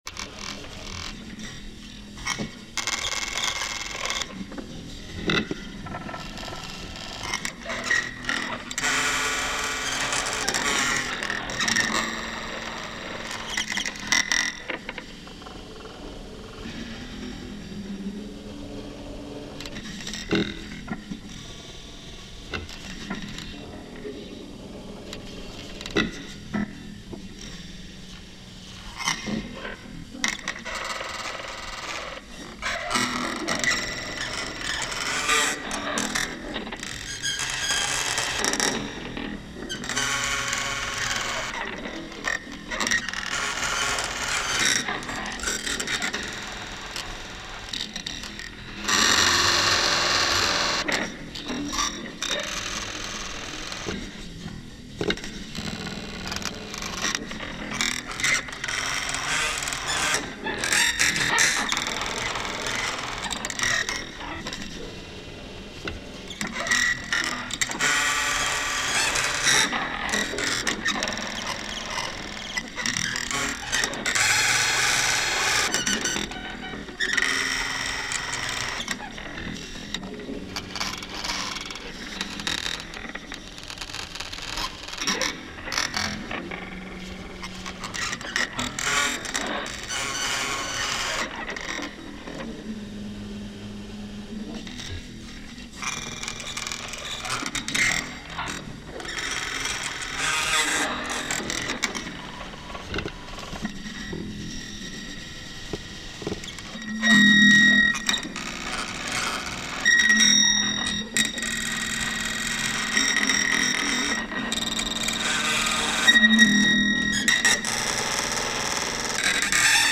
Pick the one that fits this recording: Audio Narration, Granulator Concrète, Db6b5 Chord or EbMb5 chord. Granulator Concrète